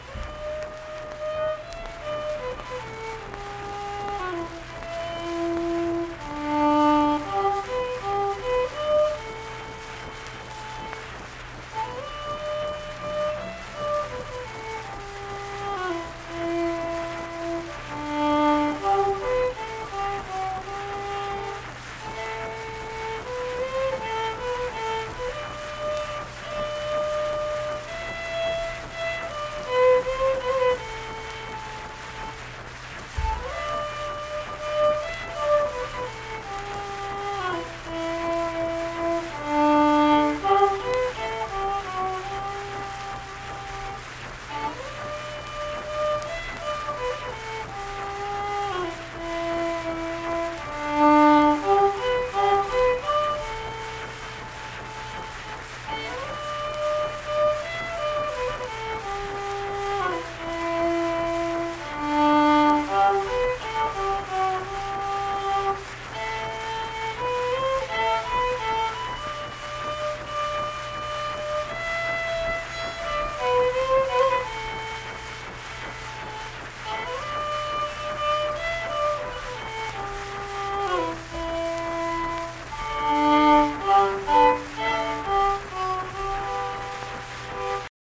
Unaccompanied fiddle music performance
Verse-refrain 2(1).
Instrumental Music, Hymns and Spiritual Music
Fiddle